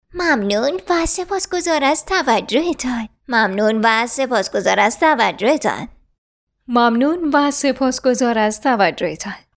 Female
Kid